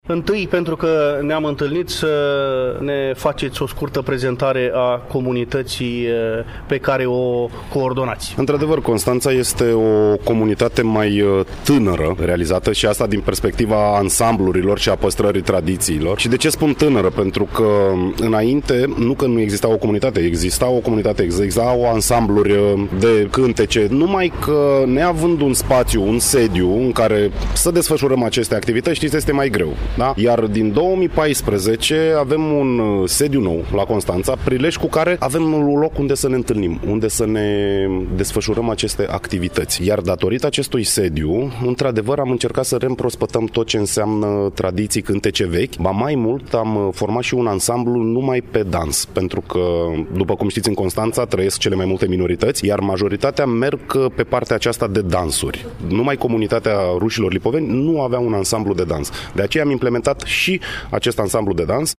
L-am întâlnit la manifestarea de la Rădăuți, Suceava, despre care, spre finalul dialogului pe care-l difuzăm în ediția de astăzi a emisiunii noastre, își exprimă opinia.